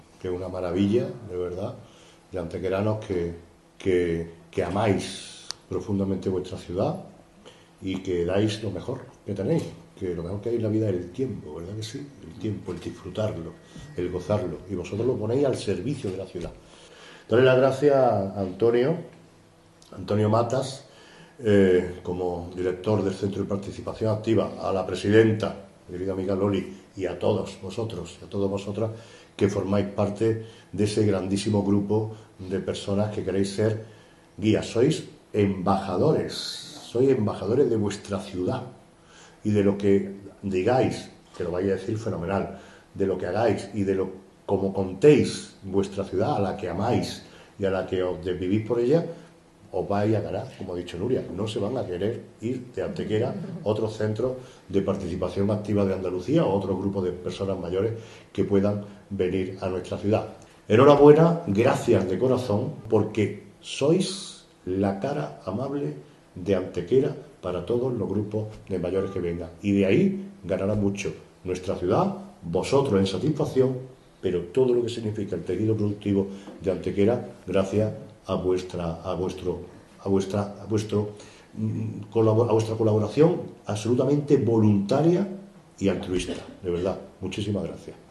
El alcalde Manolo Barón ha presidido hoy el acto de clausura desarrollado en el MVCA.
Cortes de voz